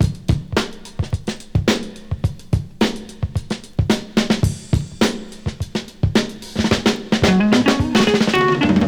• 108 Bpm Drum Groove G Key.wav
Free drum groove - kick tuned to the G note.
108-bpm-drum-groove-g-key-AdQ.wav